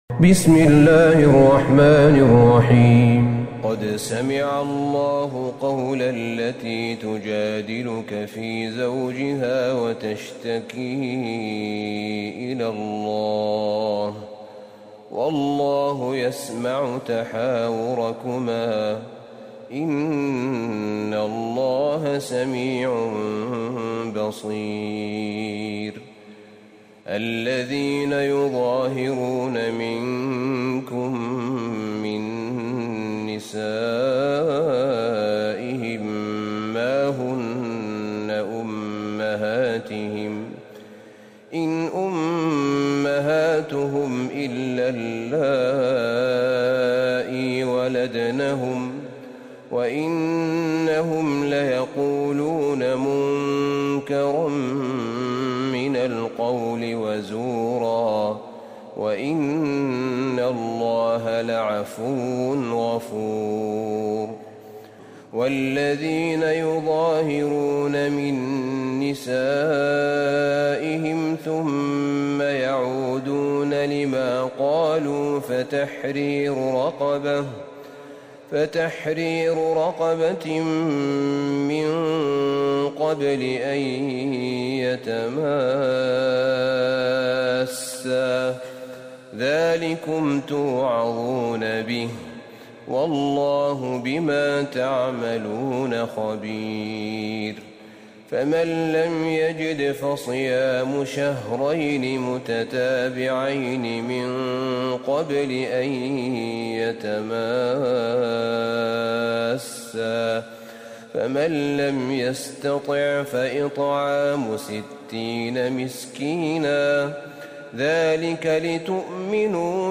سورة المجادلة Surat Al-Mujadilah > مصحف الشيخ أحمد بن طالب بن حميد من الحرم النبوي > المصحف - تلاوات الحرمين